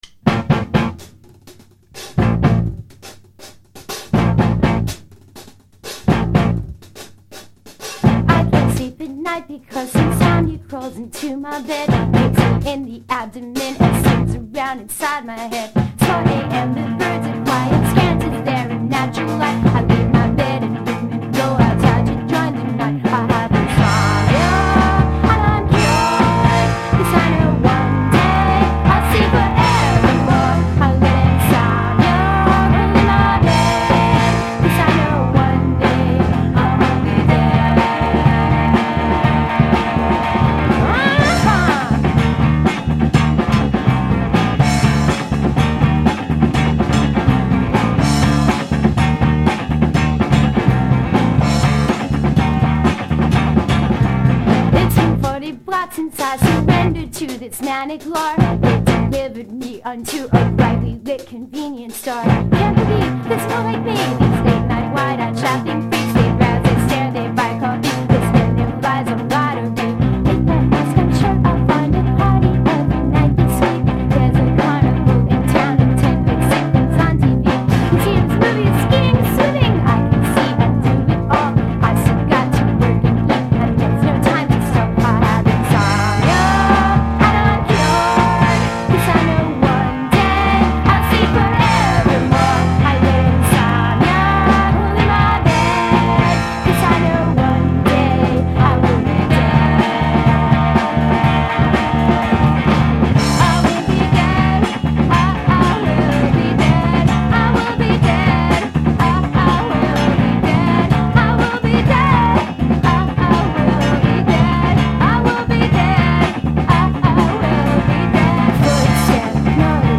playful angelic vocals